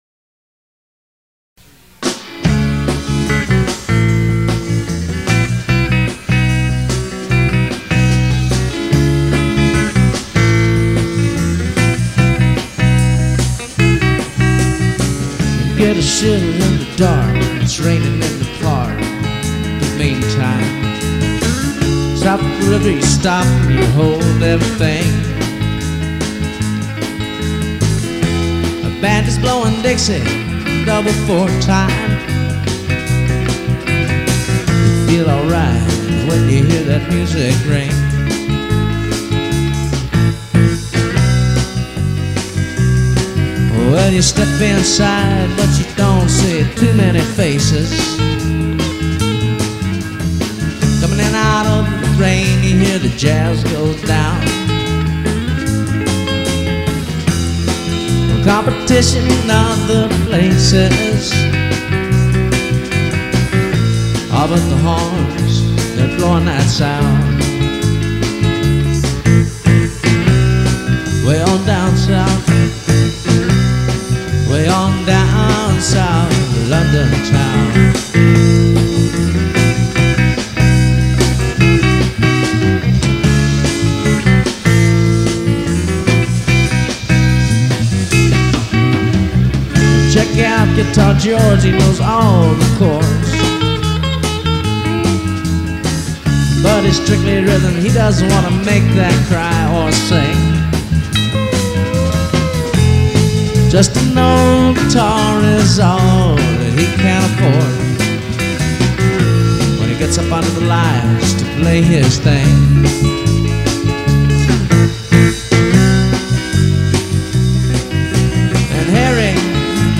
singing and playing drums
bass
guitar